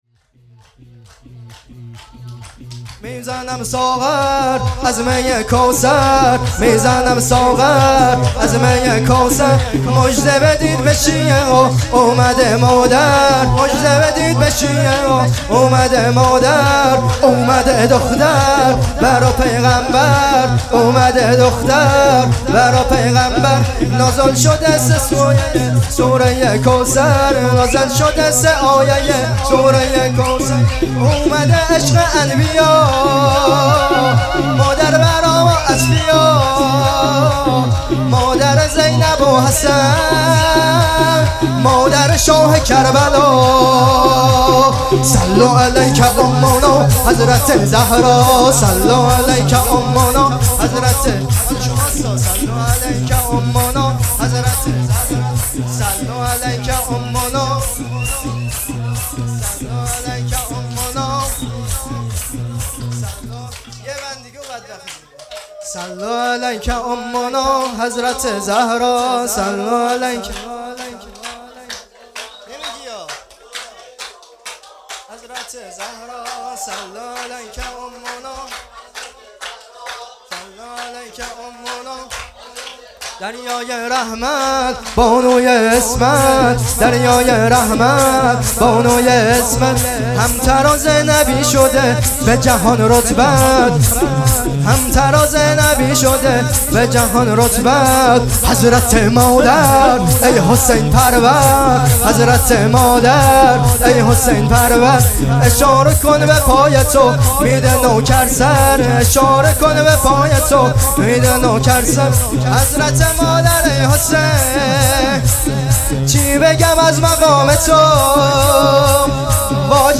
جشن ولادت حضرت زهرا سلام الله ۱۶-۱۱-۹۹